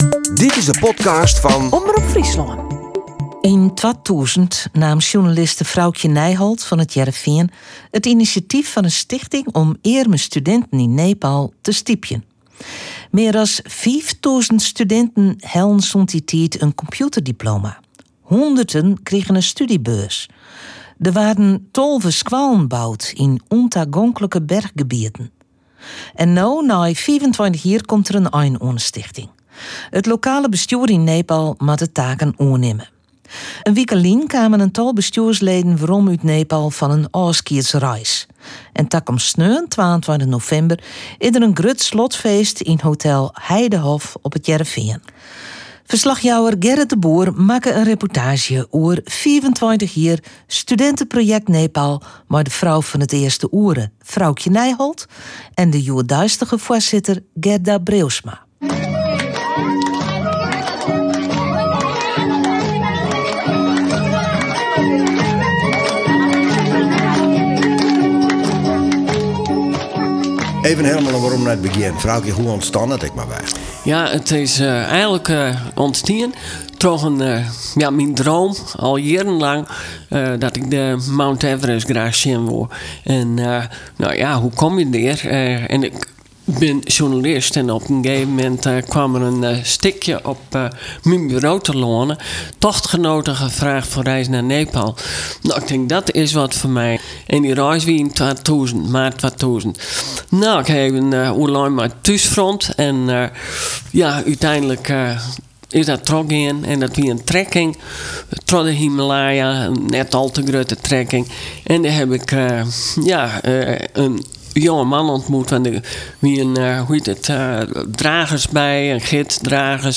reportaazje